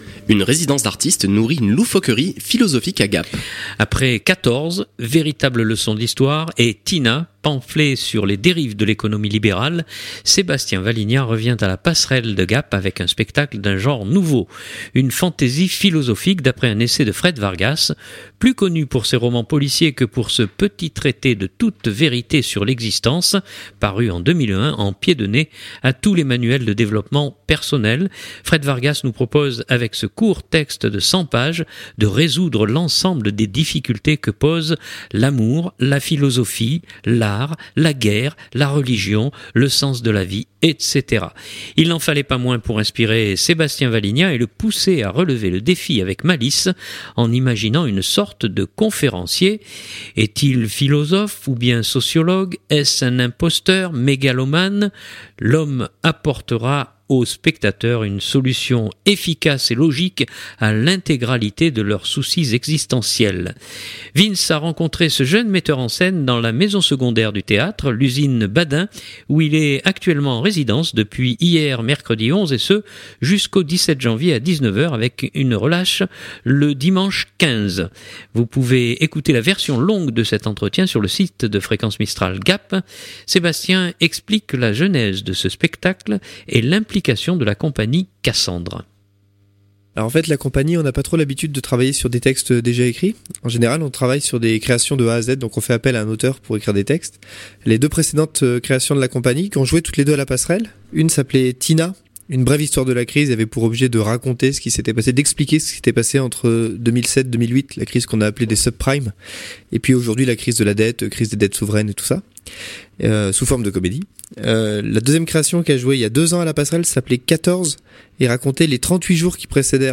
Sachez que vous pourrez également écouter la version longue de cet entretien sur le site de Fréquence Mistral Gap.